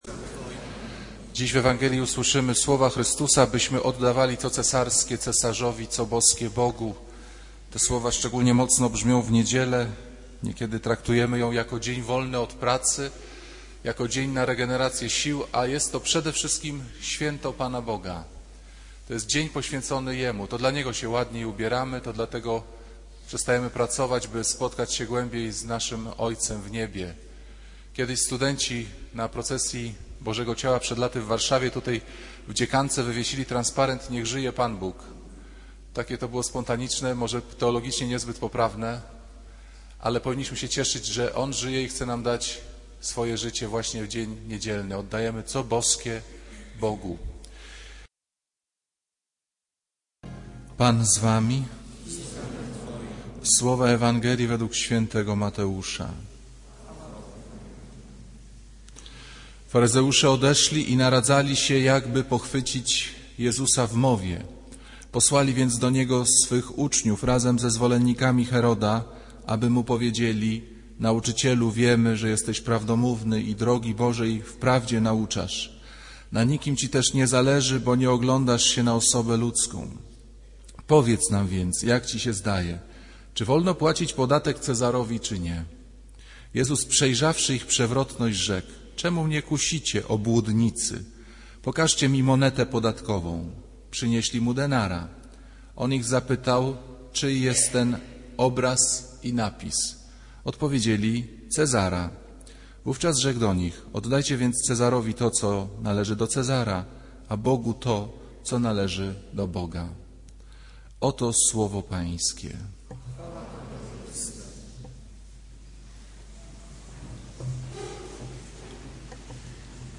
Kazanie z 19 października 2008r.
kościół św. Anny w Warszawie « Kazanie z 12 października 2008r.